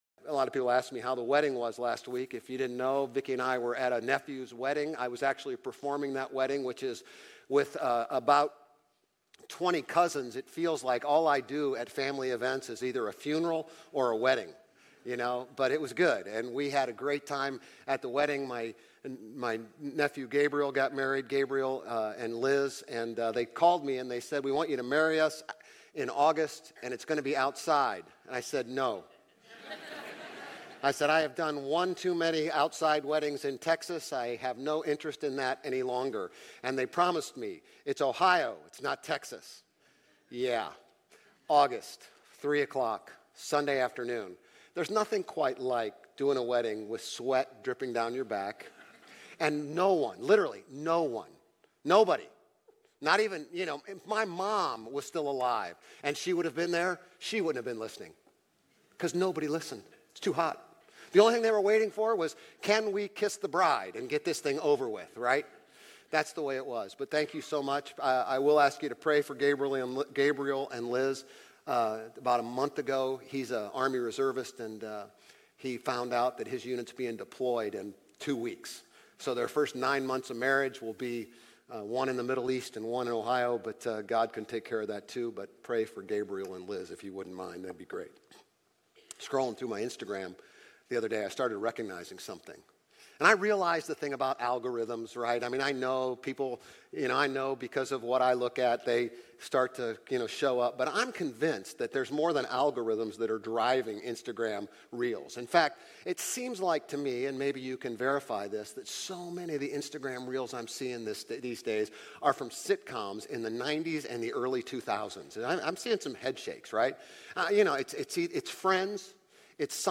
Grace Community Church Old Jacksonville Campus Sermons 8_11 Old Jacksonville Campus Aug 11 2024 | 00:28:02 1x 00:00 / 00:28:02 Subscribe Share RSS Feed Share Link Embed